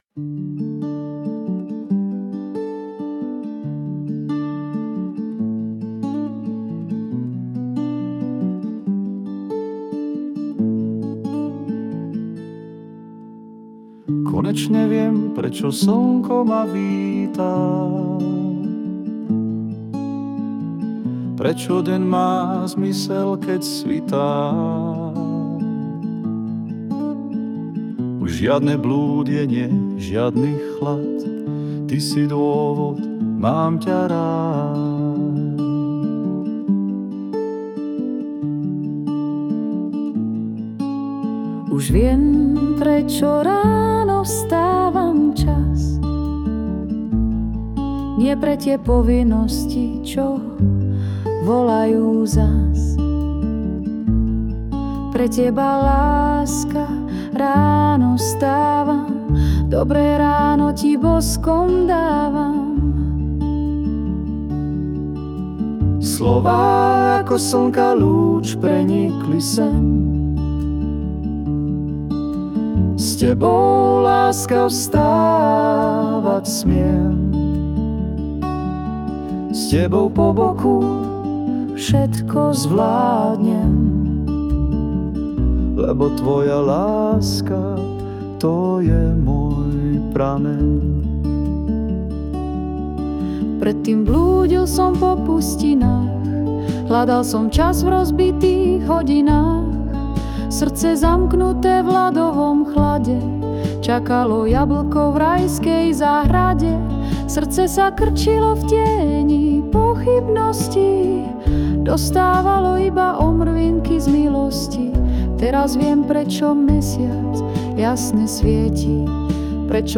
Hudba a spev Ai